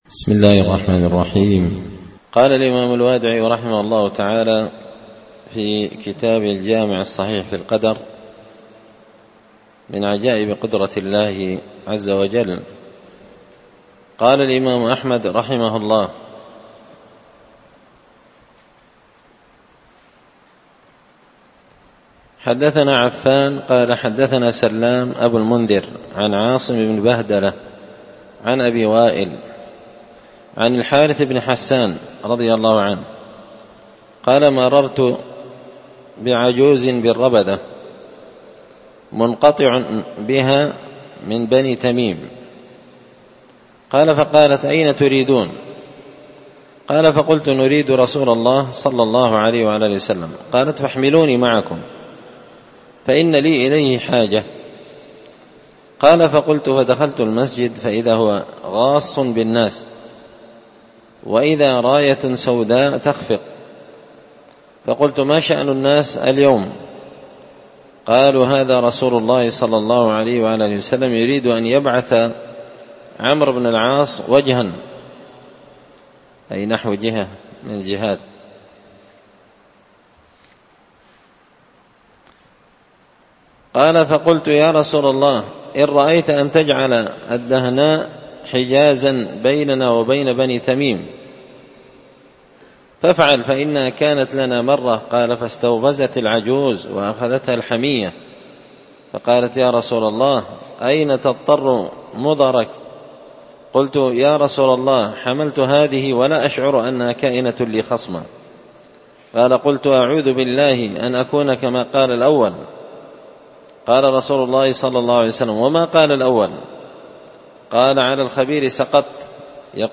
الدرس 118 فصل من عجائب قدرة الله الخارقة للعادة
دار الحديث بمسجد الفرقان ـ قشن ـ المهرة ـ اليمن